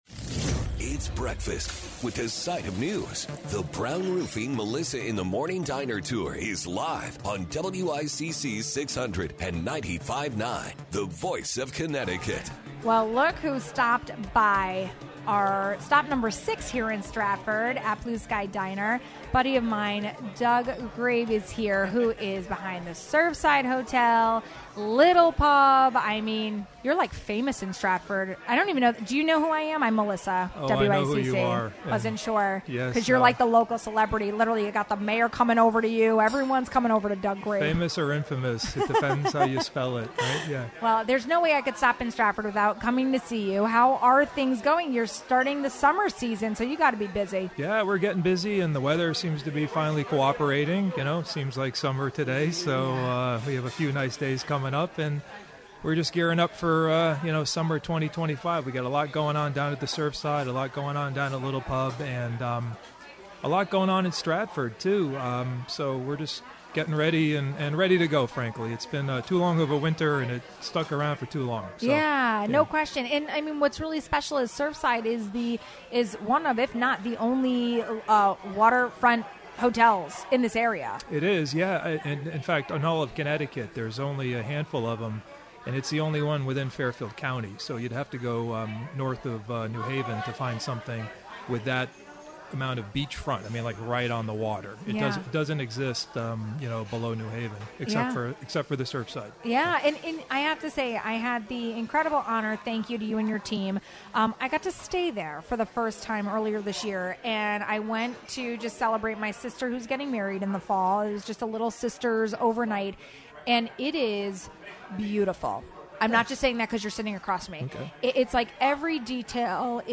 at the Blue Sky Diner in Stratford!